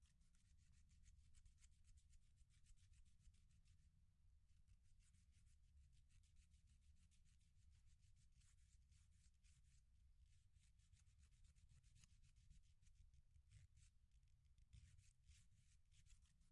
锉刀钉
描述：指甲的不同变化，刮擦，刮擦，研磨。使用Zoom H6现场录音机和立体声麦克风录制。
Tag: 文件 美甲 指甲刮伤 申请记录 OWI 立体声